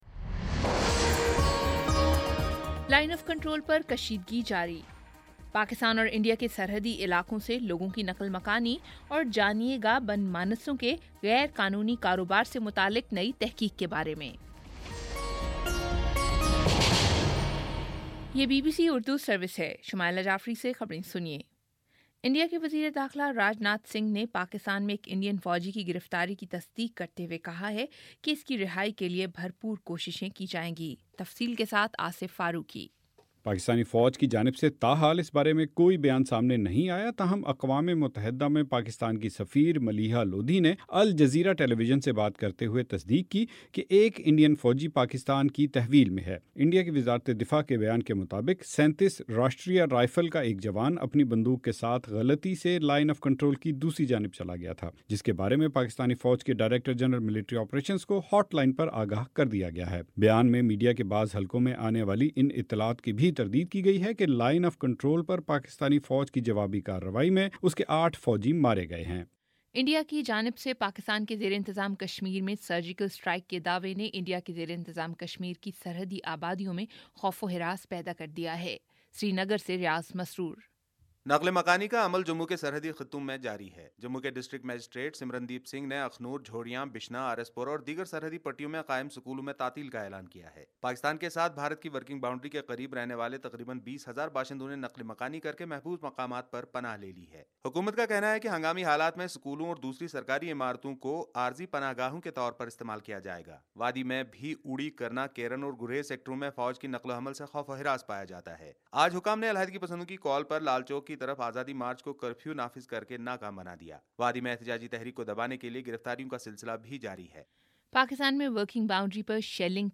ستمبر30 : شام چھ بجے کا نیوز بُلیٹن